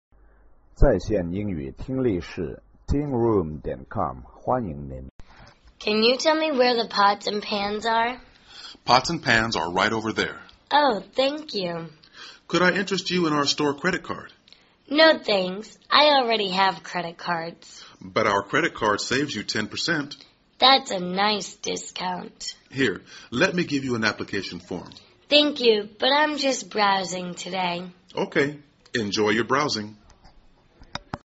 购物英文对话-Applying for a Credit Card(1) 听力文件下载—在线英语听力室